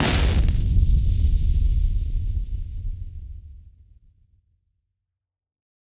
BF_HitSplosionB-03.wav